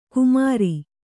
♪ kumāri